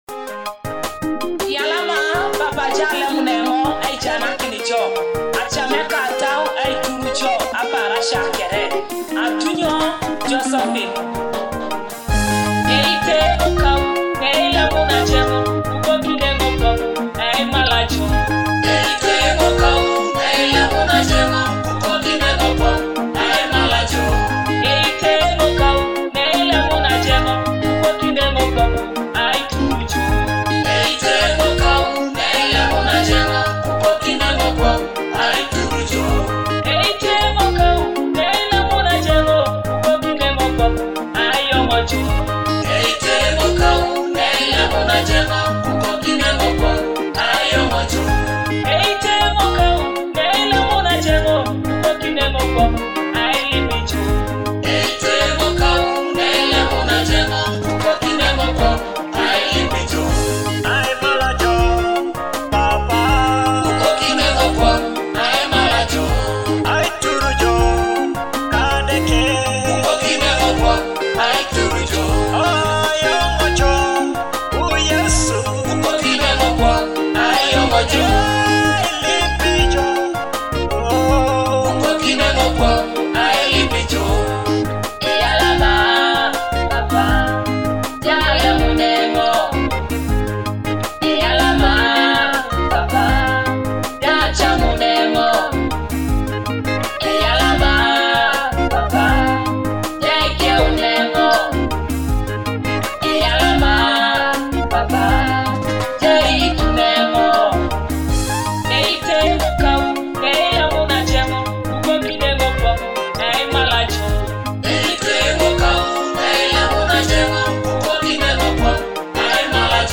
Teso gospel worship